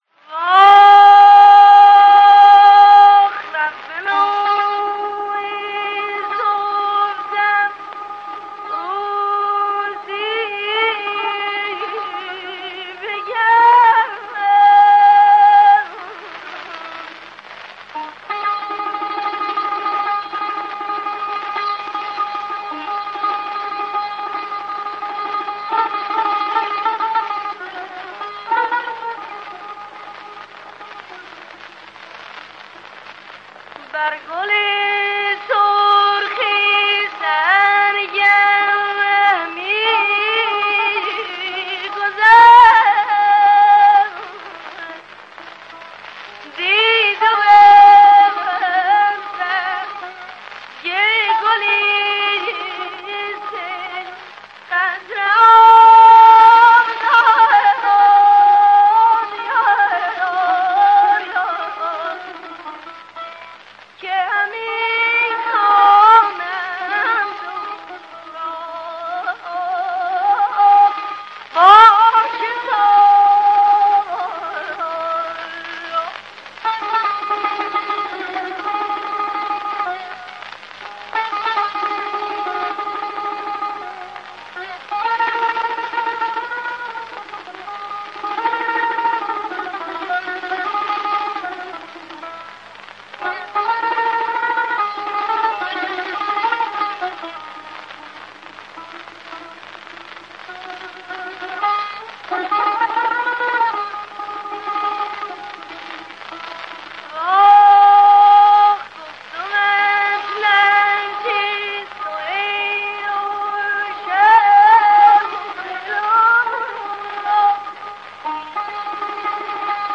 Târ